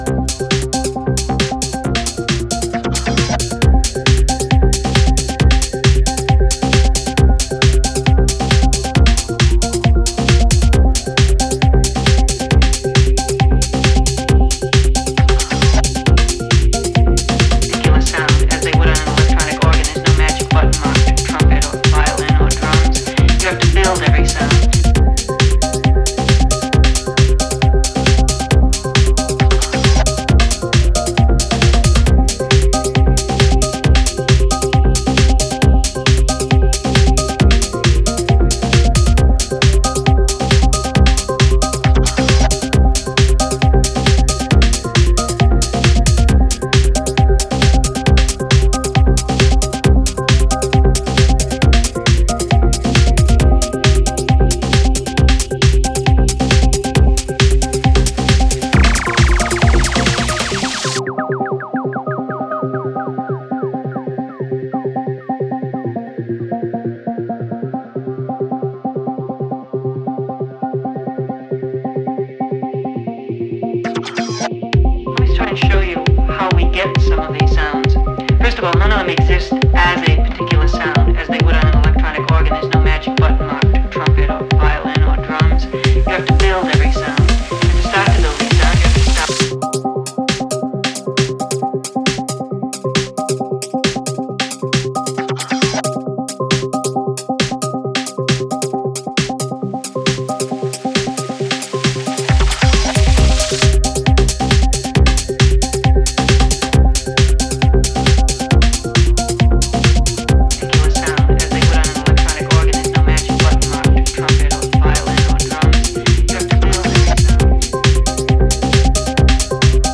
dark and hypnotic sounds
syncopated rhythms